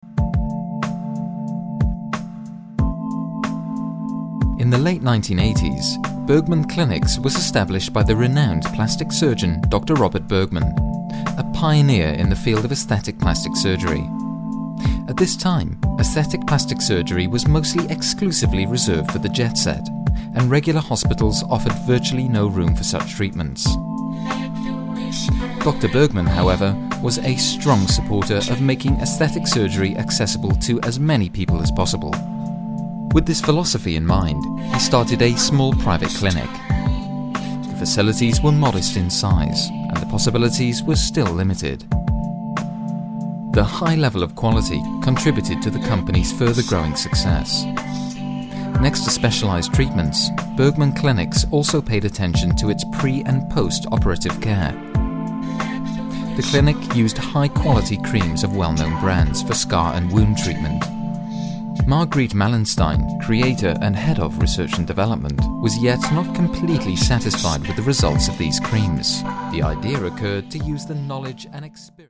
Sprecher englisch.
britisch
Sprechprobe: Industrie (Muttersprache):